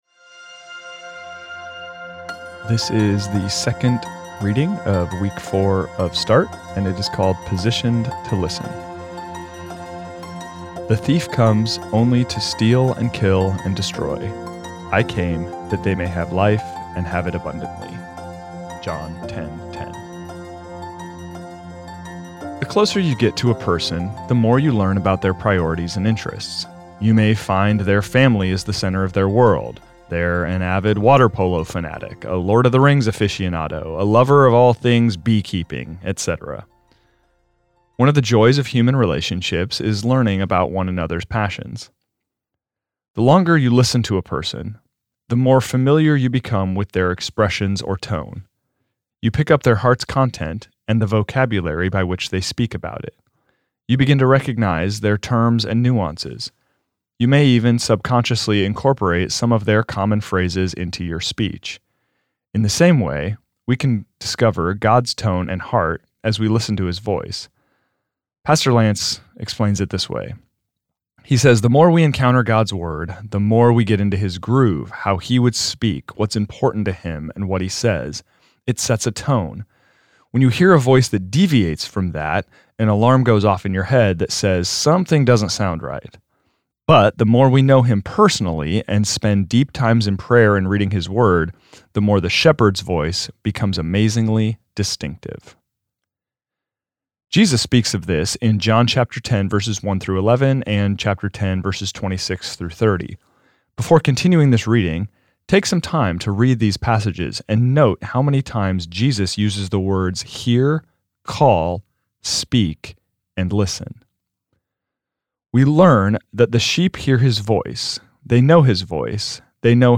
This is the audio recording of the second reading of week four of Start, entitled Positioned to Listen.